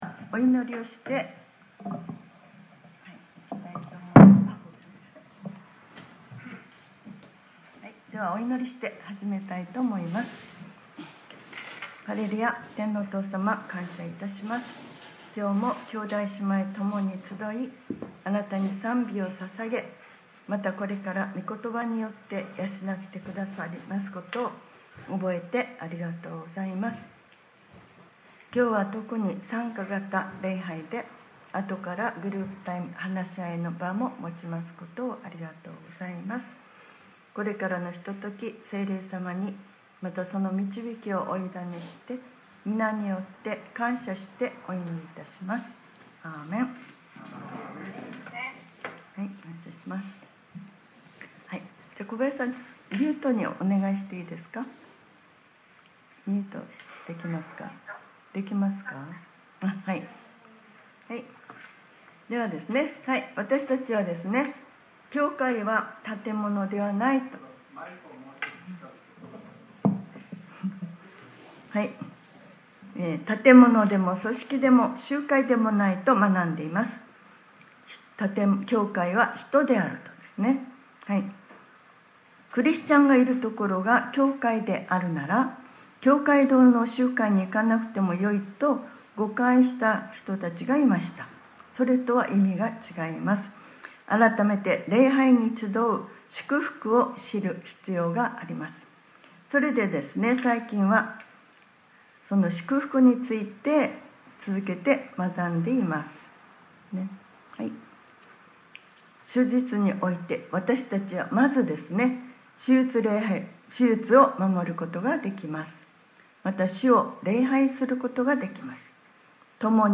2025年04月27日（日）礼拝説教『 安息日を守る祝福:集まる 』